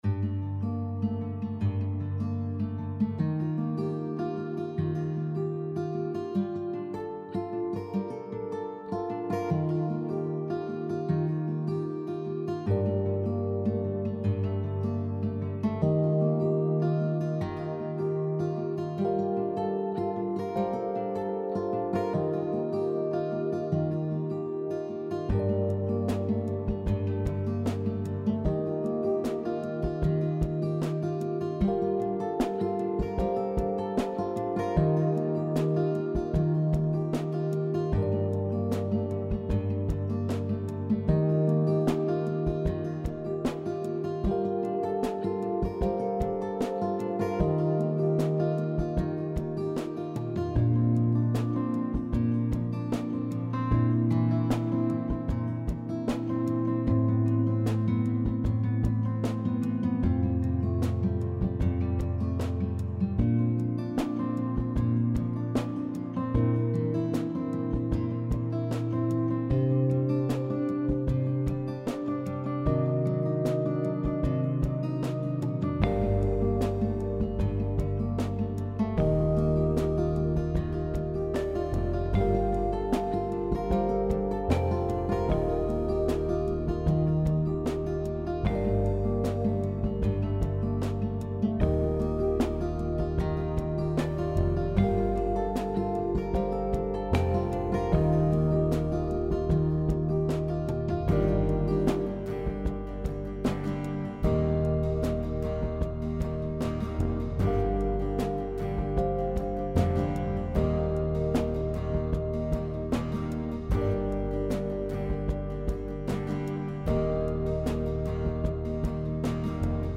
Melancholic Music
Genre: Emotional Feel free to use my music, it would be great if you credit me.